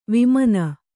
♪ vimana